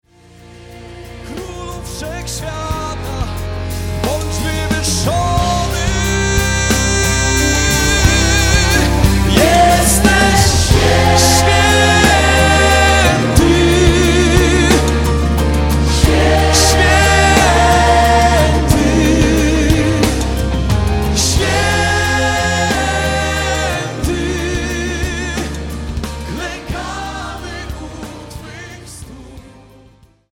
Płyta koncertowa